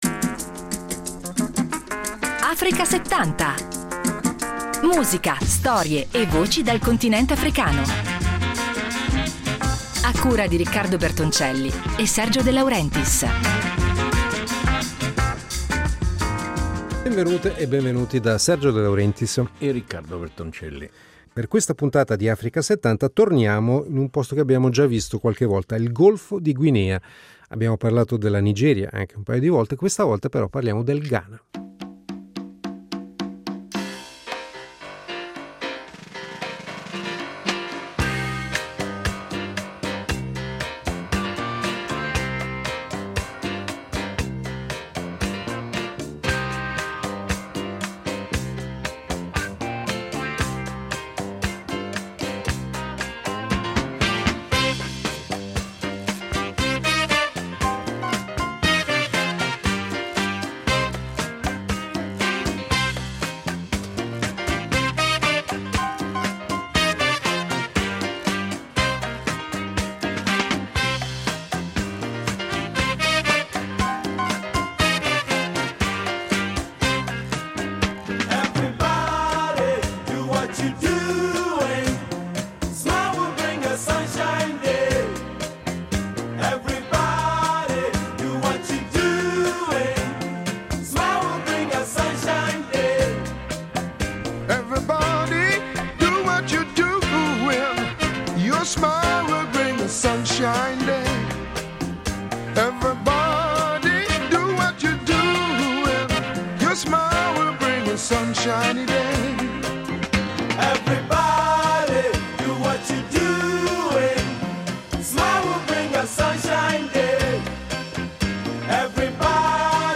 Funk e Highlife: il sound unico del Ghana anni ’70